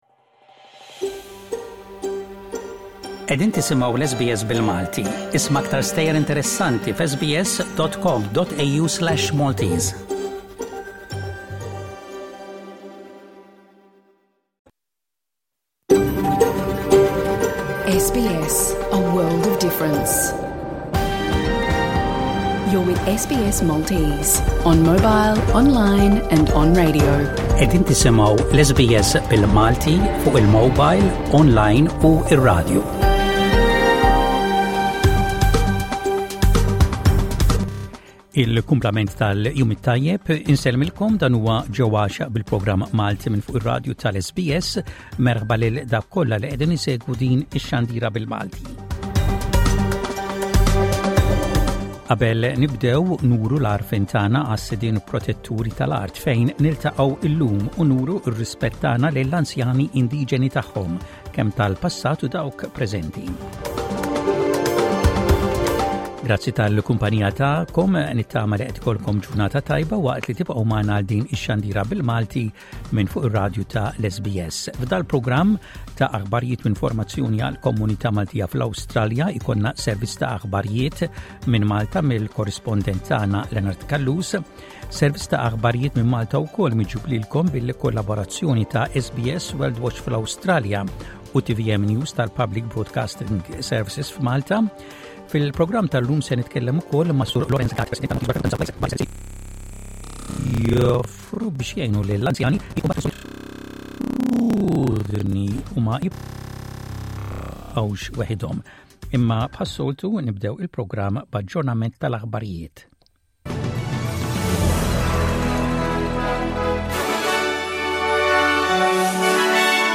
Avviżi komunitarji Share